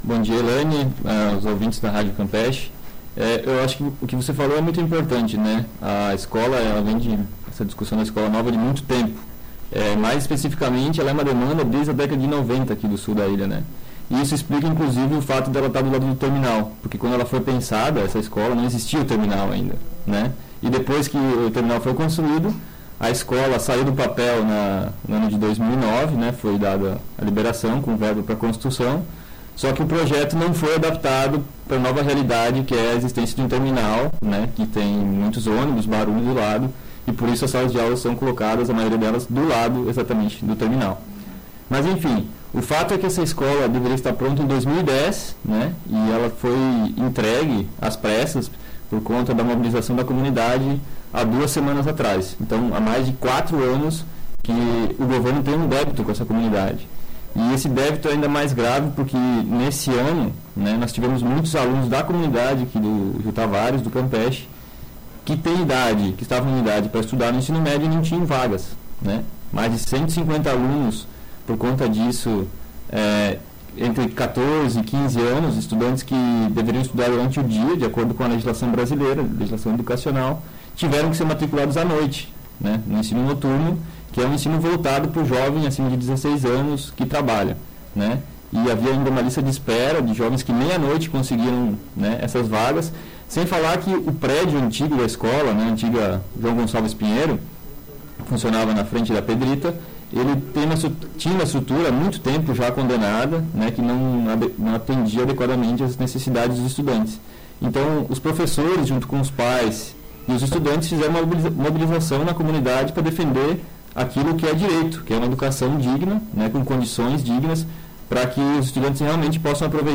Entrevista
Entrevistas radiofônicas